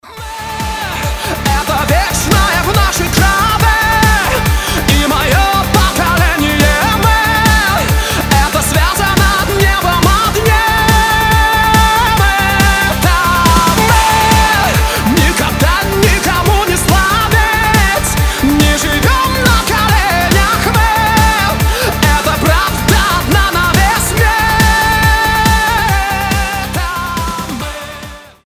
громкие рингтоны
поп